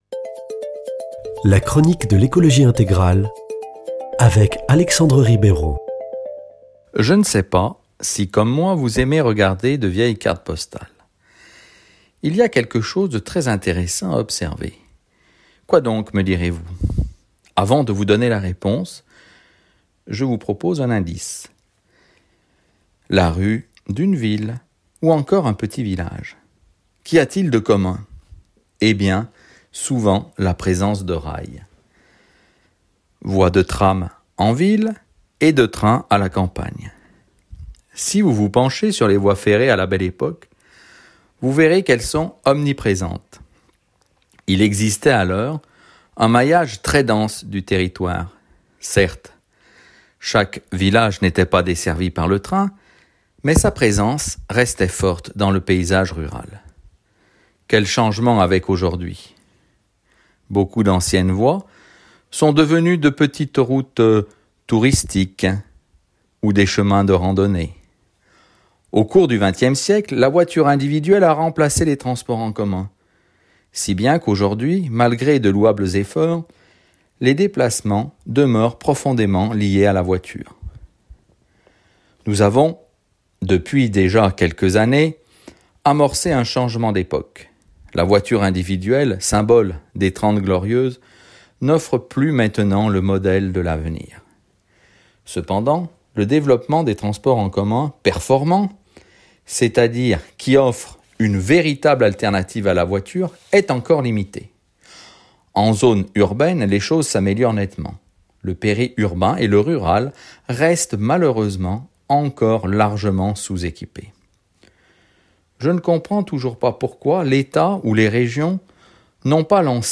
vendredi 12 août 2022 Chronique écologie intégrale Durée 3 min
Présentateur